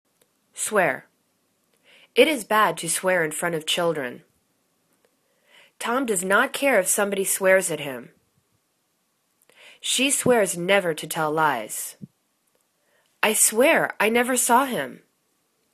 swear     /swer/    v